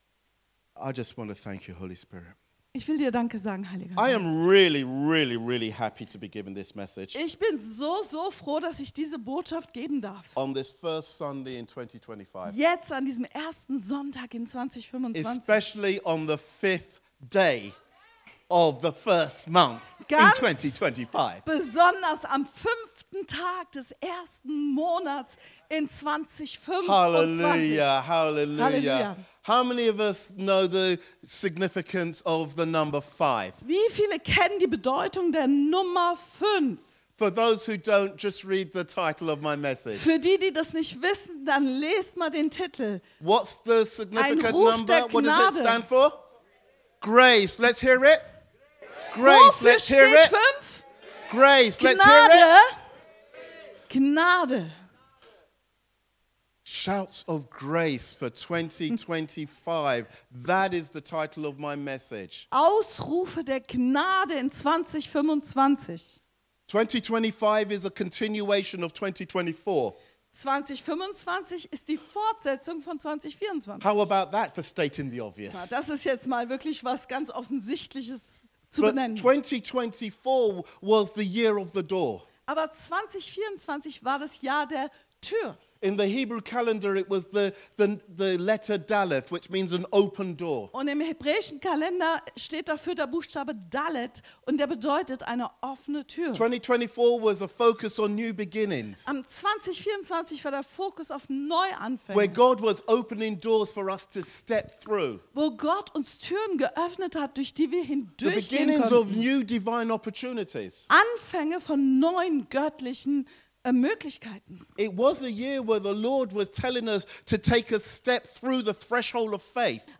Sermons from KLF